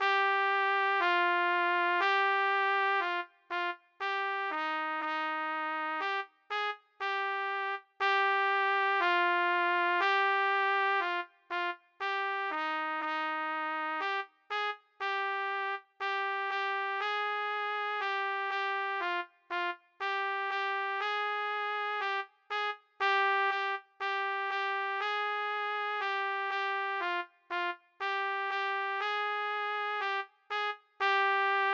B=Harmony-for intermediate players